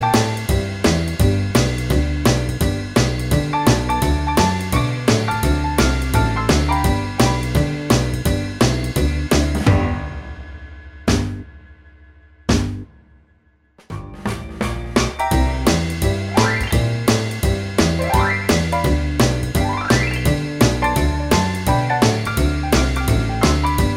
Minus Lead Guitar Rock 'n' Roll 3:18 Buy £1.50